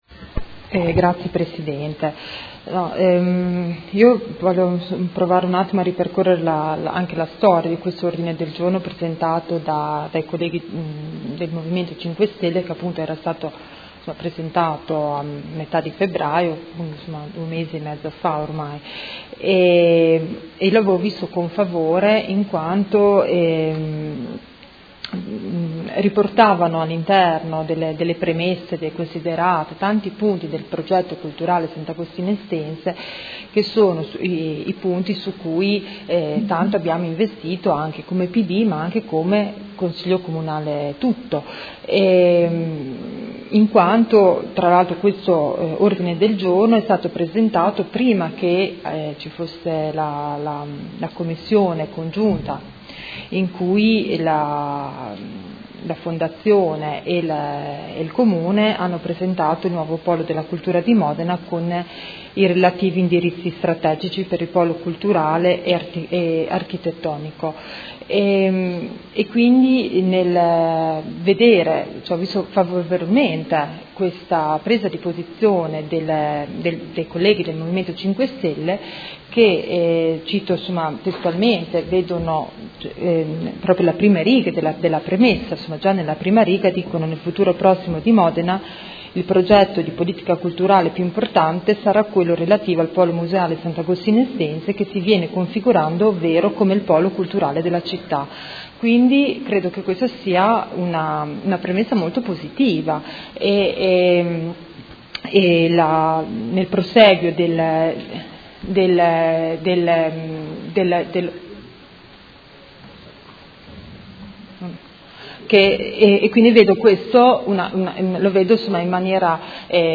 Seduta del 27/04/2017 Dibattito. Ordine del Giorno presentato dal Gruppo Movimento 5 Stelle avente per oggetto: Progetto culturale Sant’Agostino – Estense e finanziamenti